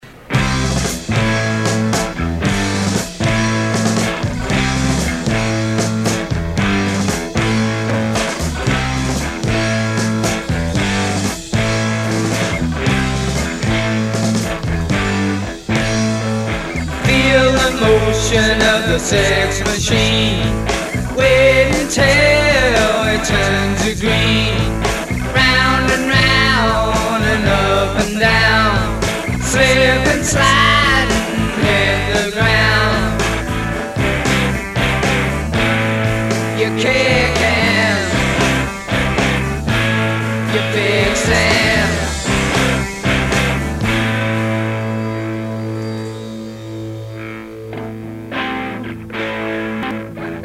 re-mastered from the original tapes